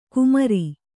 ♪ kumari